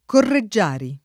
[ korre JJ# ri ]